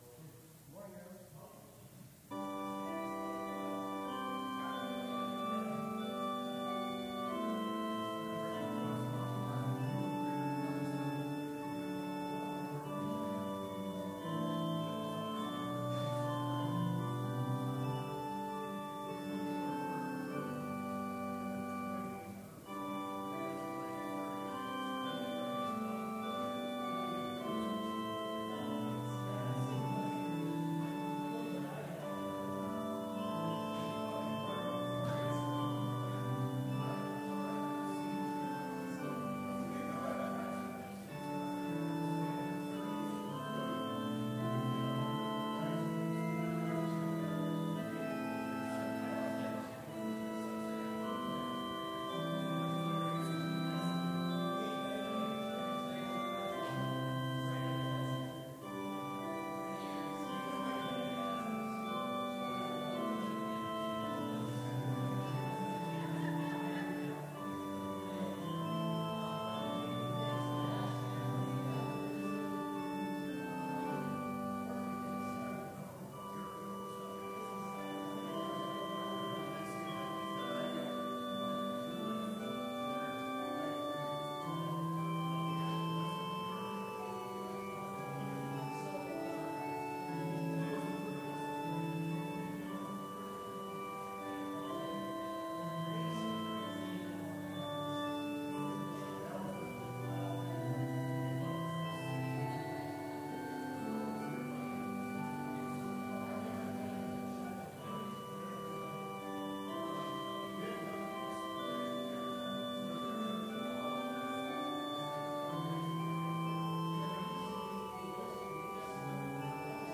Complete service audio for Chapel - February 26, 2019